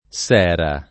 Sera [ S$ ra ]